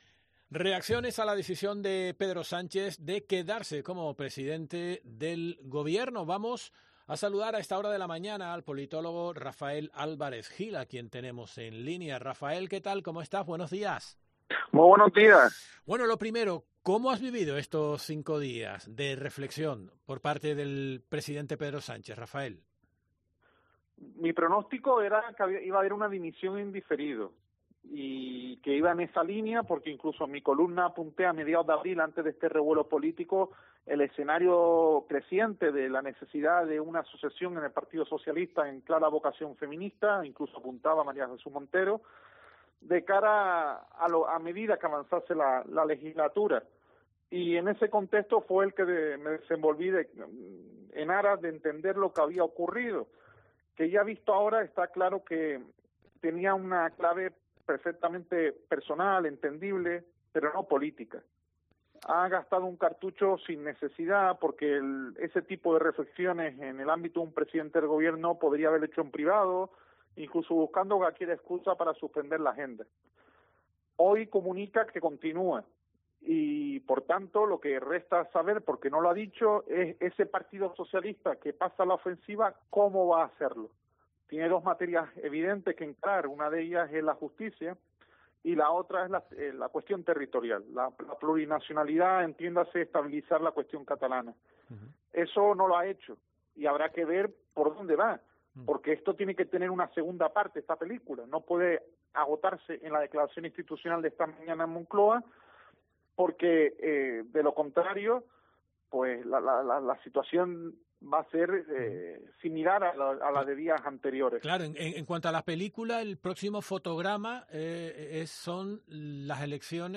La crítica de un politólogo canario a Sánchez: "Debe hacerse cargo del estado emocional del país"